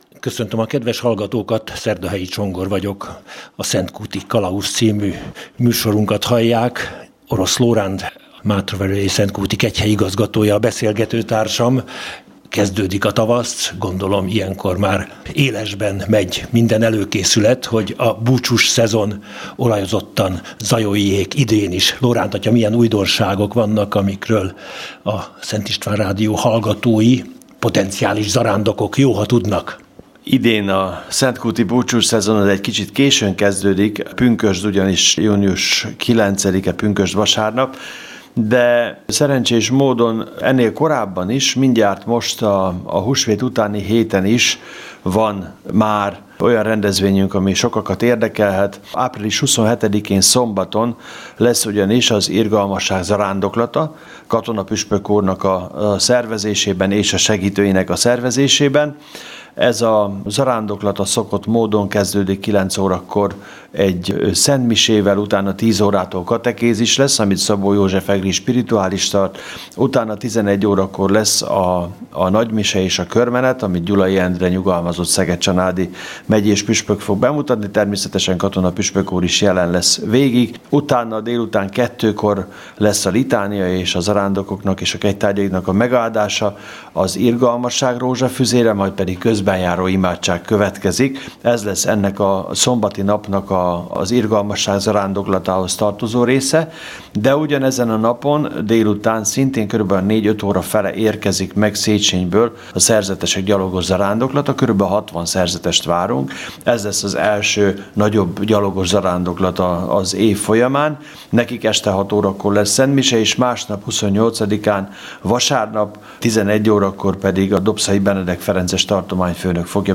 A műsor itt meghallgatható.